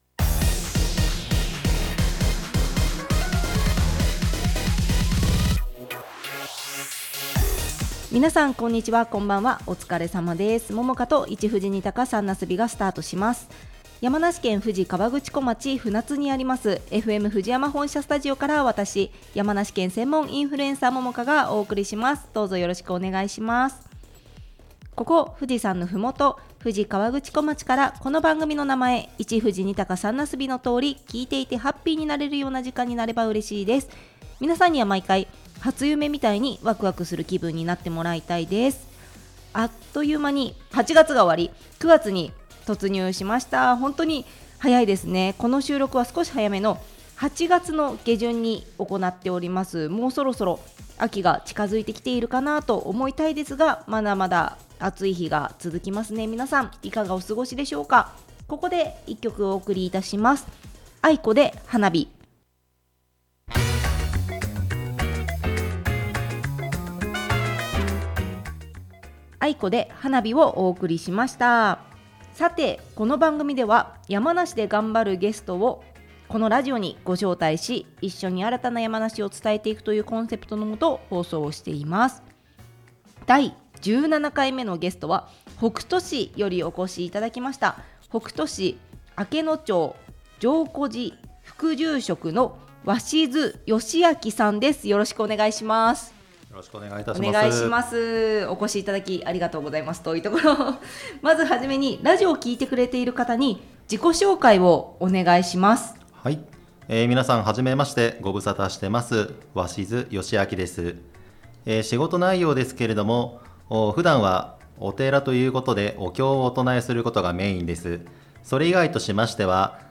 （ネット配信の為楽曲はカットしています）